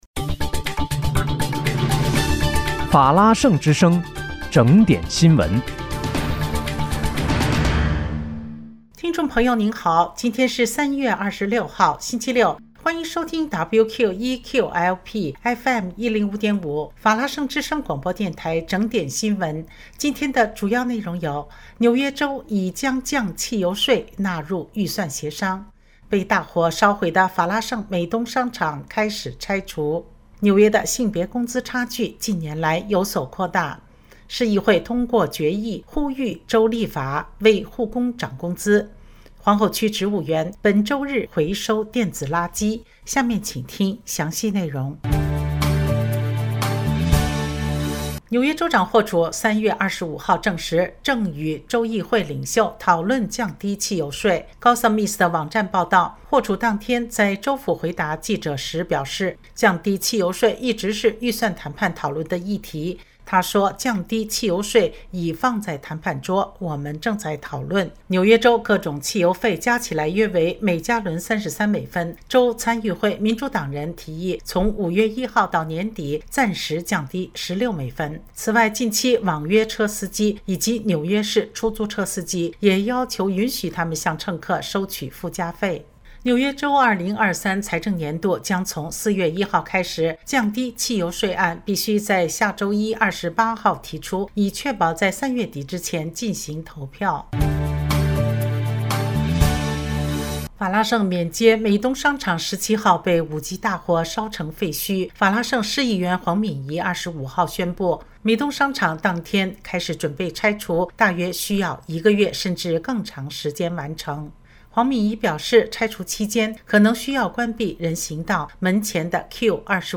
3月26日（星期六）纽约整点新闻